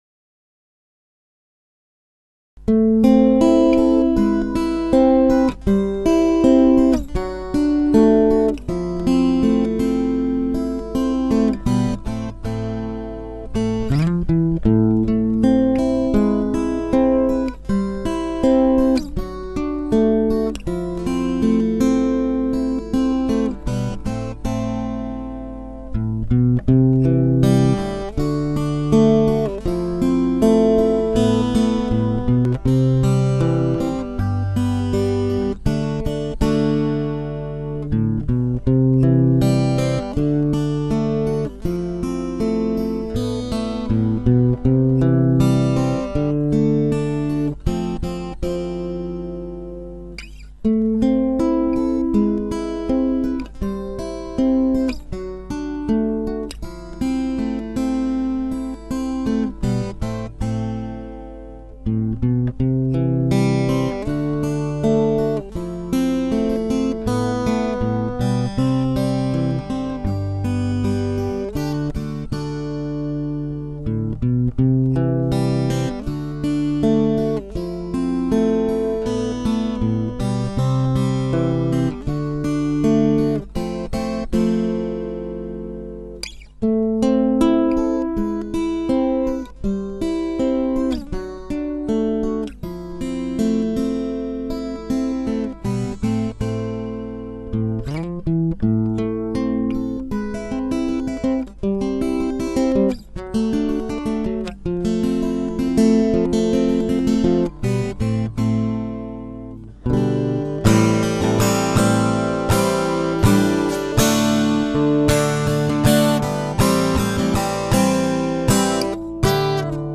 from Rock to Jazz to Soul to Rhythm and Blues on guitar